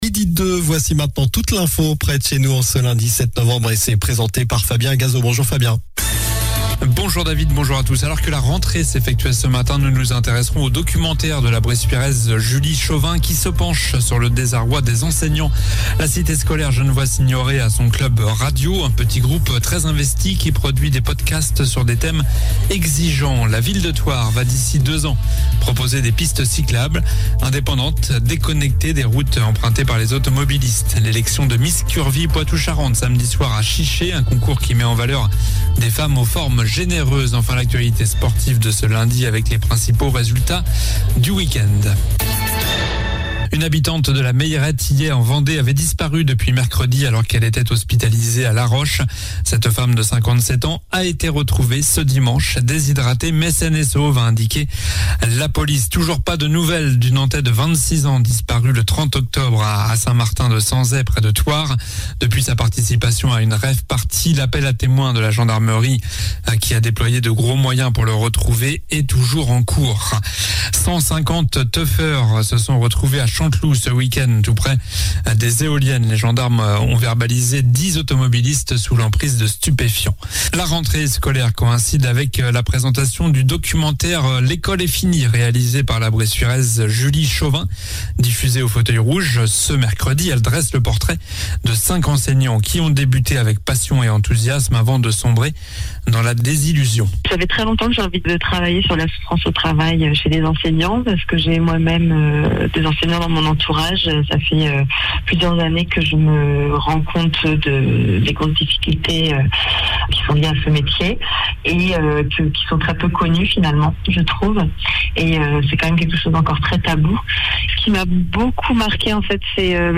Journal du lundi 07 novembre (midi)